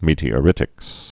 (mētē-ə-rĭtĭks)